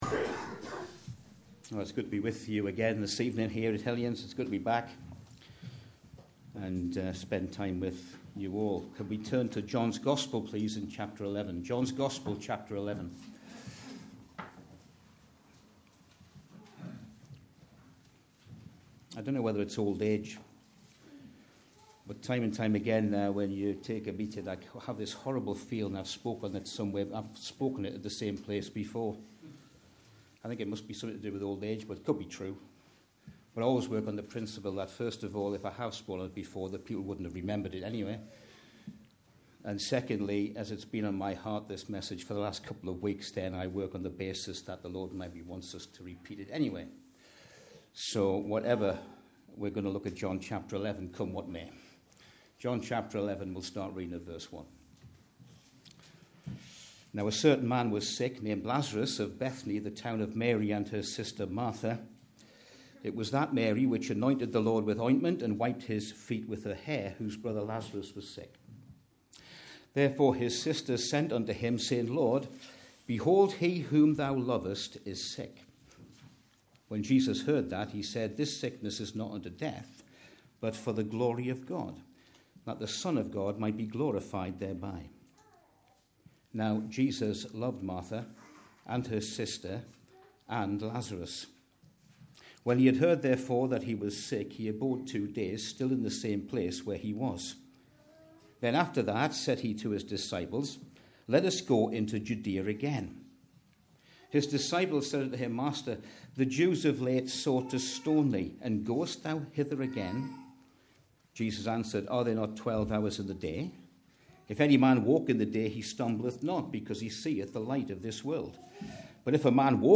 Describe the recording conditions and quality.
A recording of our Regular Ministry Meeting Series.